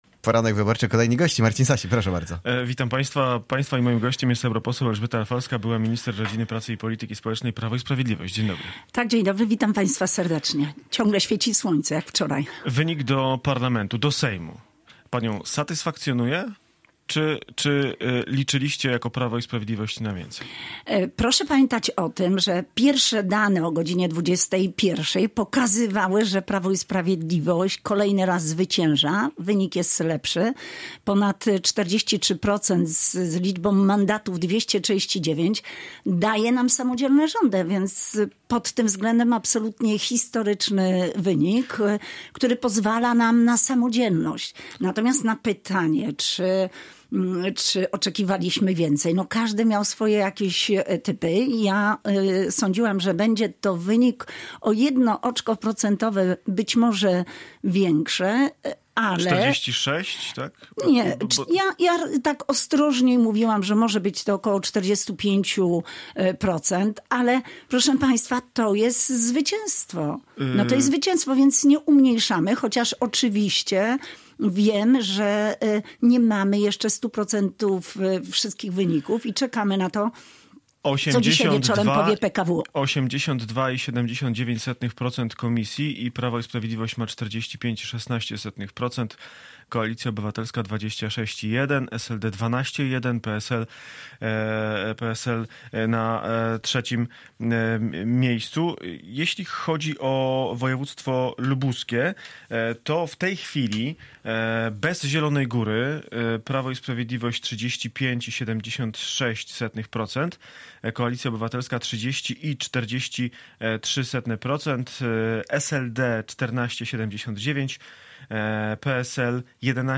Dzisiaj w redakcji Radia Gorzów sporo gości. Odwiedzają nas ci, którzy startowali w wyborach do Parlamentu, ale nie tylko.